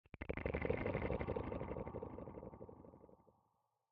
Minecraft Version Minecraft Version 1.21.5 Latest Release | Latest Snapshot 1.21.5 / assets / minecraft / sounds / block / conduit / short1.ogg Compare With Compare With Latest Release | Latest Snapshot